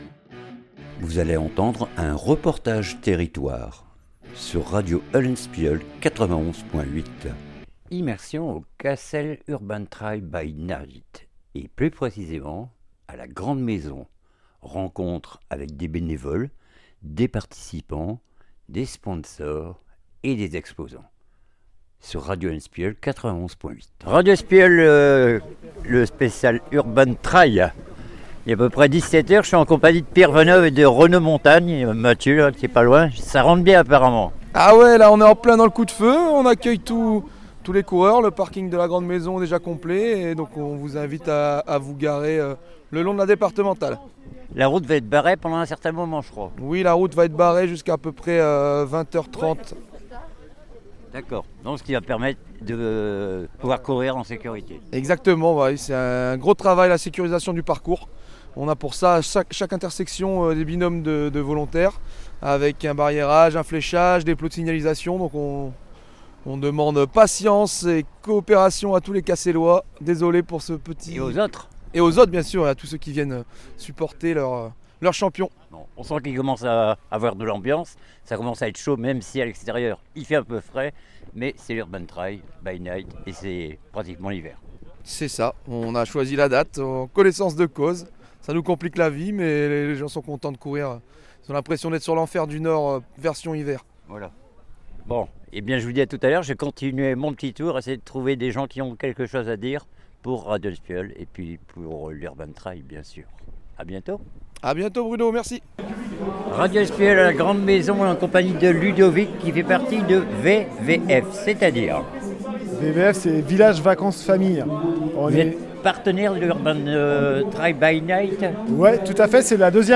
REPORTAGE A LA GRANDE MAISON URBAN TRAIL